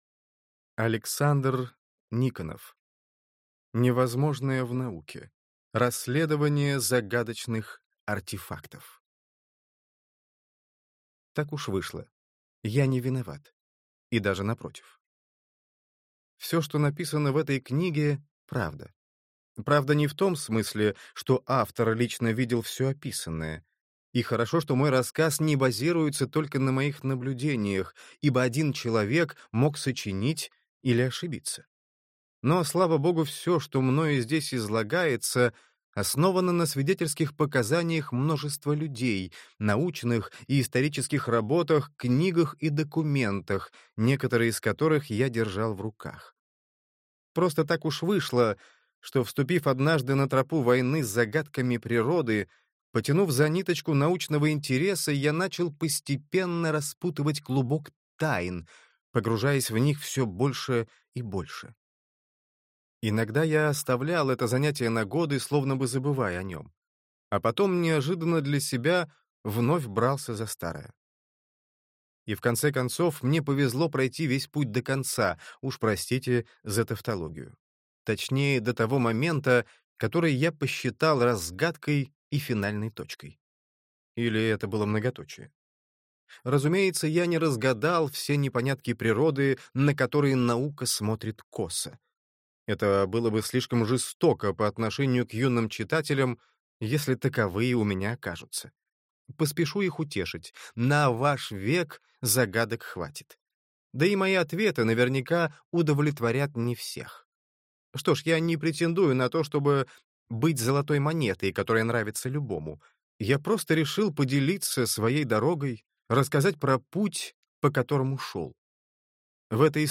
Аудиокнига Невозможное в науке. Расследование загадочных артефактов | Библиотека аудиокниг